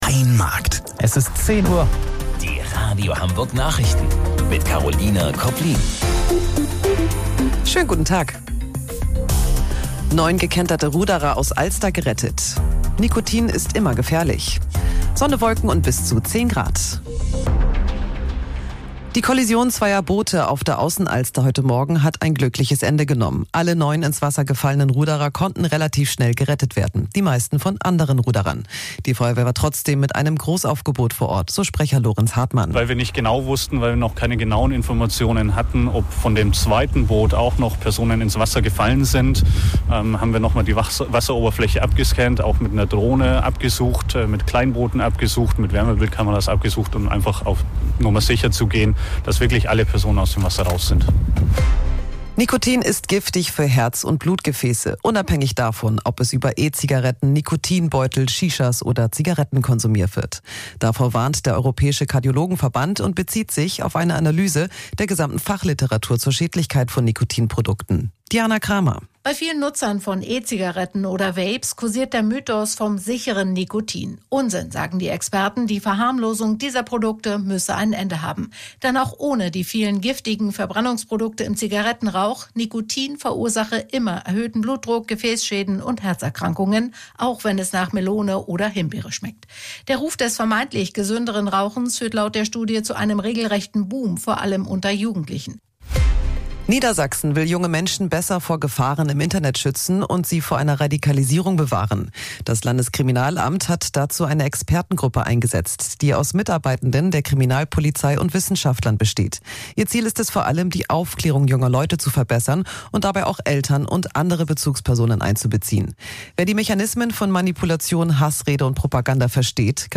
Radio Hamburg Nachrichten vom 18.12.2025 um 10 Uhr